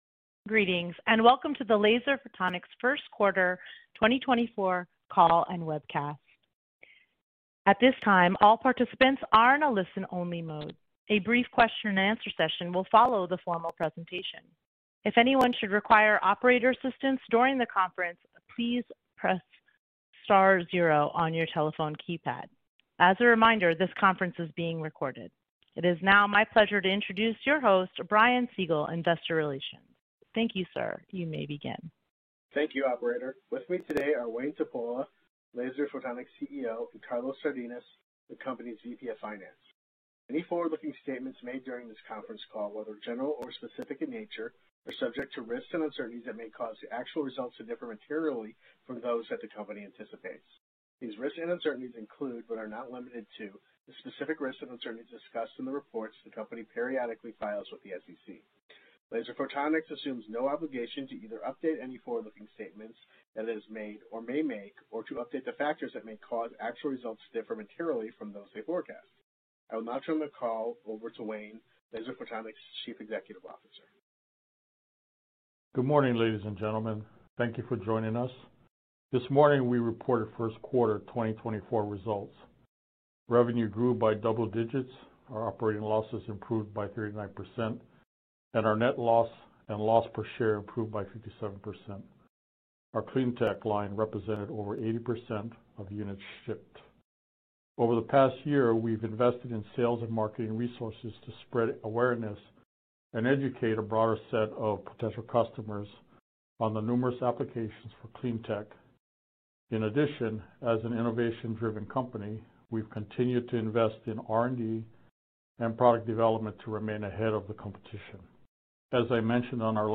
Get insights into our financial performance, strategic initiatives, and future growth plans directly from our leadership team.